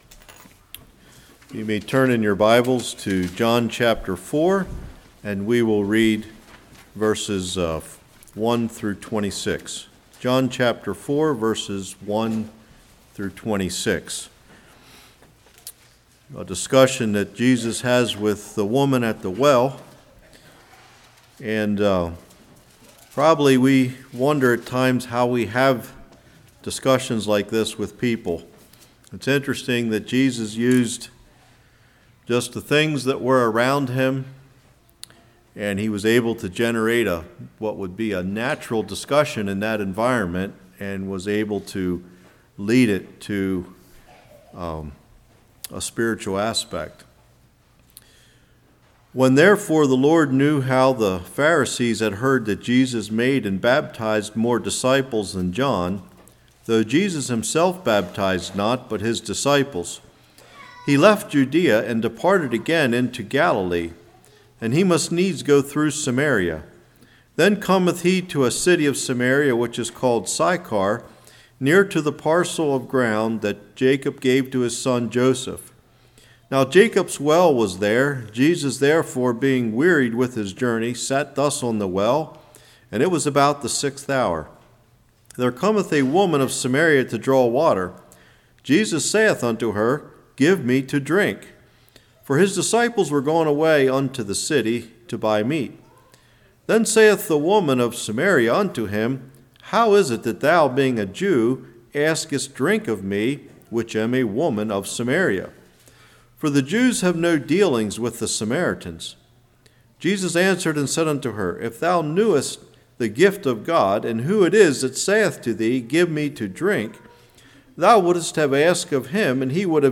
John 4:1-26 Service Type: Morning How Do you Share The Gospel?